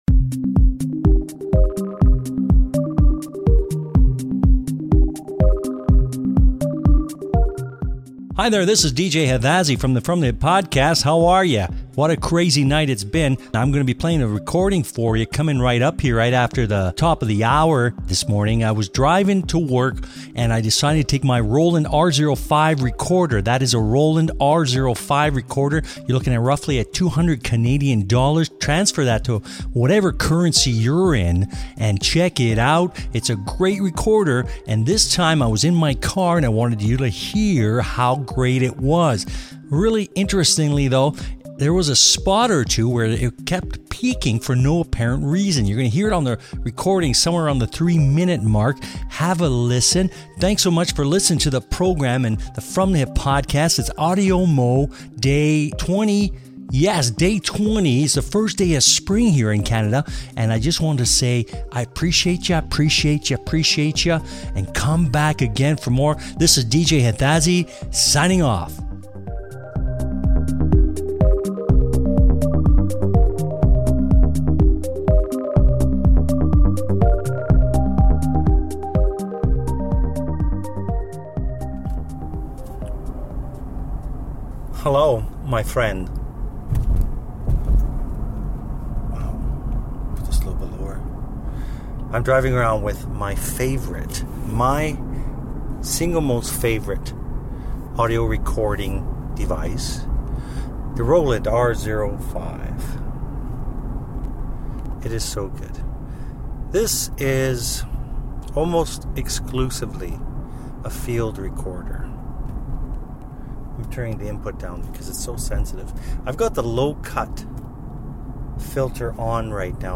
More Roland R-05 Recording
I take the R-05 along for a car ride.